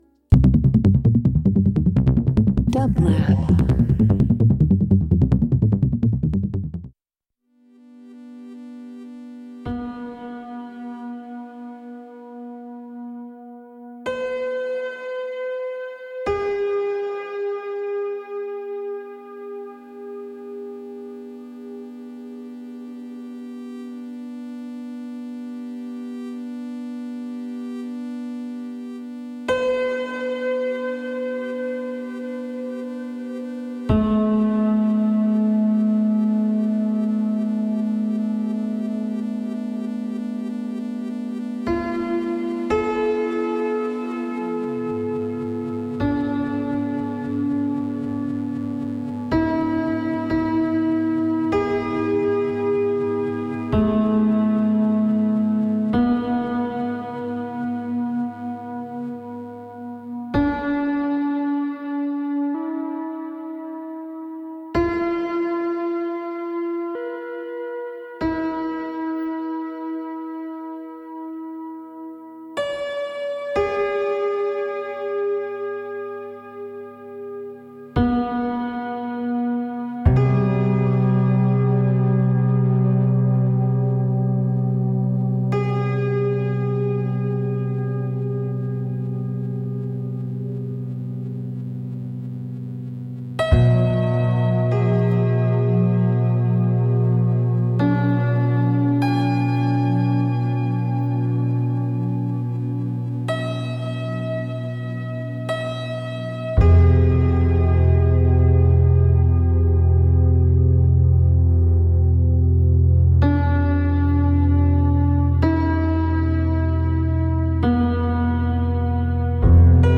Ambient Electronic Experimental Synth